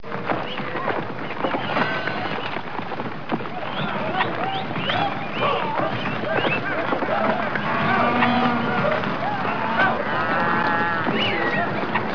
دانلود صدای حیوانات جنگلی 61 از ساعد نیوز با لینک مستقیم و کیفیت بالا
جلوه های صوتی
برچسب: دانلود آهنگ های افکت صوتی انسان و موجودات زنده دانلود آلبوم صدای حیوانات جنگلی از افکت صوتی انسان و موجودات زنده